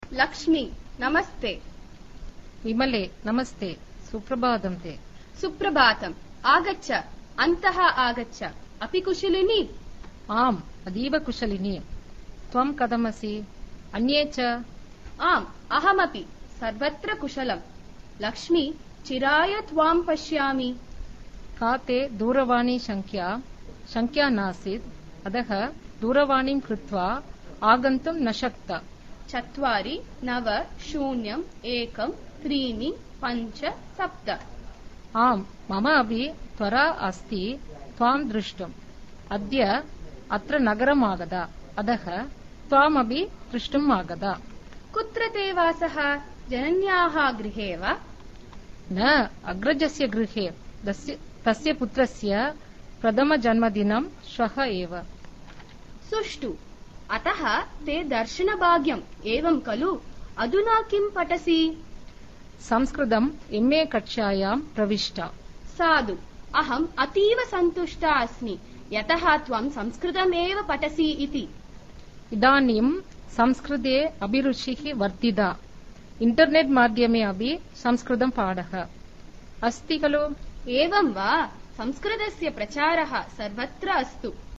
Lesson 06.1 A Conversation